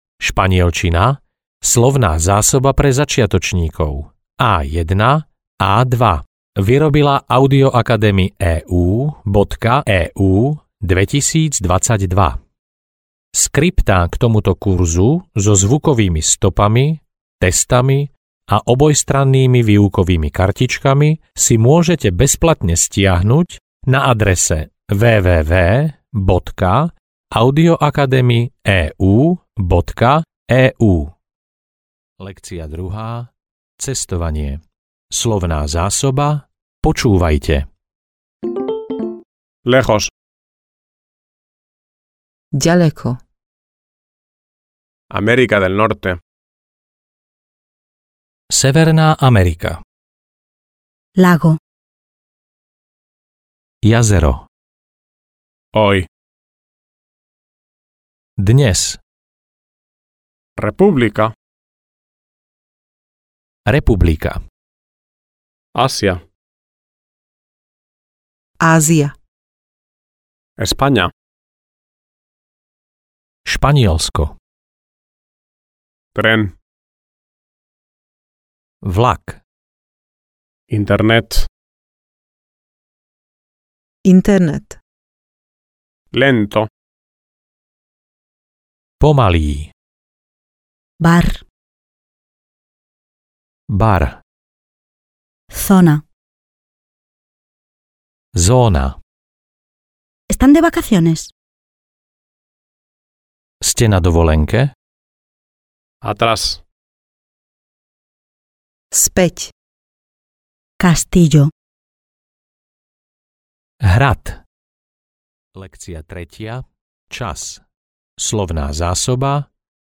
Španielčina pre začiatočníkov A1-A2 audiokniha
Ukázka z knihy
Keď zvládnete preklad viet zo slovenčiny do španielčiny (lekcia 6) v časovej medzere pred španielským prekladom, máte vyhraté.